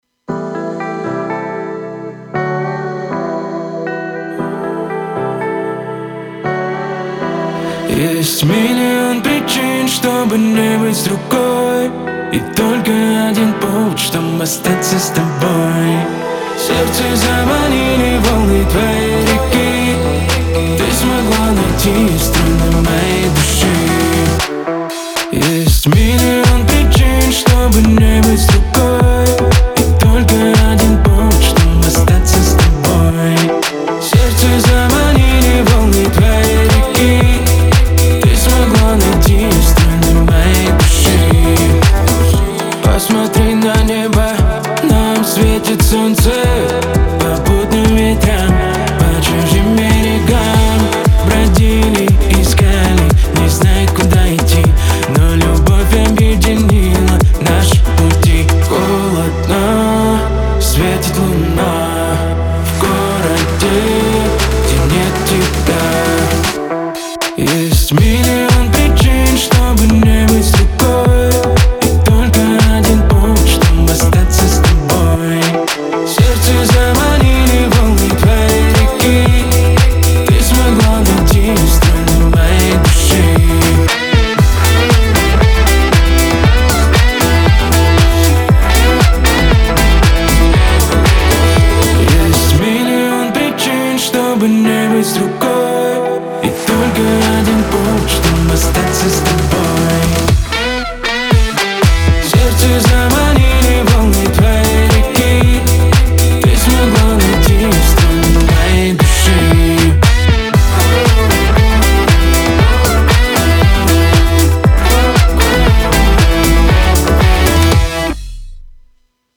диско , грусть
pop
эстрада